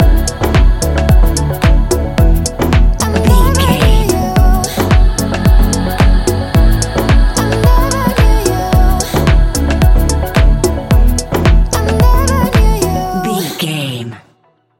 Ionian/Major
E♭
house
electro dance
synths
trance